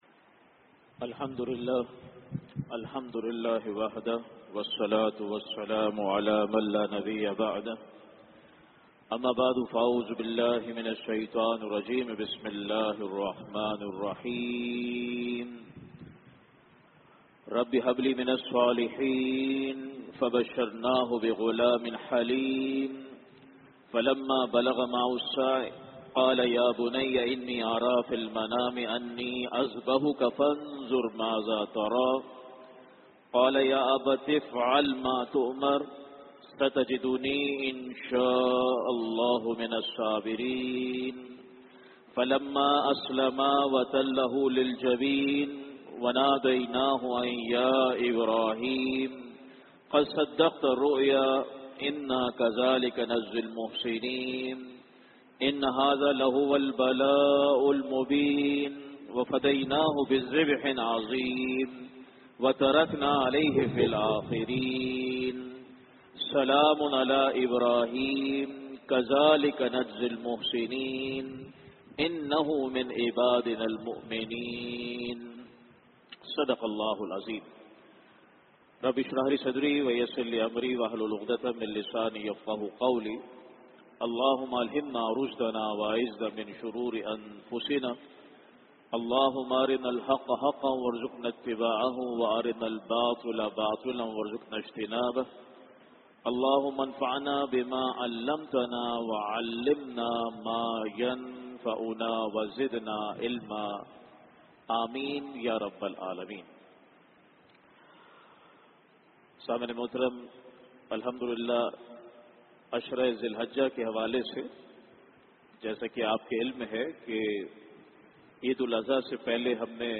Khutbat-e-Jummah (Friday Sermons)
@ Masjid Jame-ul-Quran, Gulshan-e-Maymar The Ultimate Sacrifice, The Greatest Trial — All Loves Surrendered to Allah